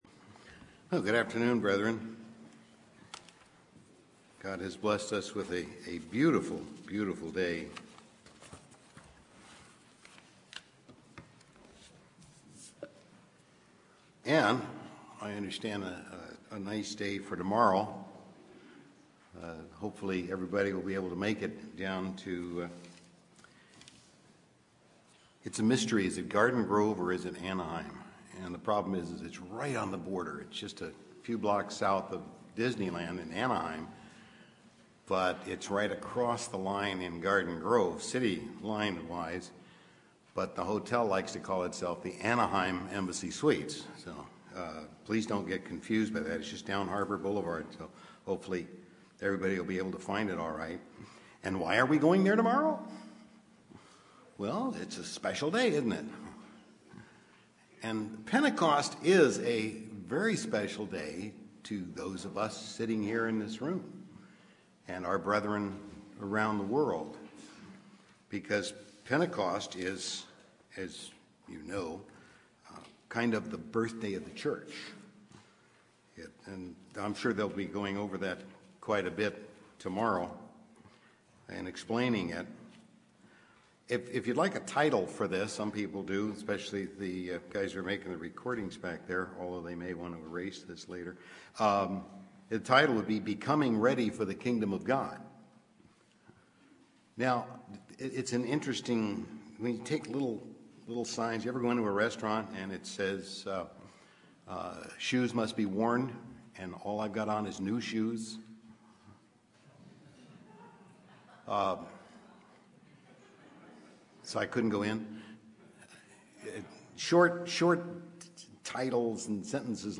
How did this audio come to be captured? This message, given just before Pentecost, reminds us that we are called out now, as first fruits, for the Kingdom of God. Given in Los Angeles, CA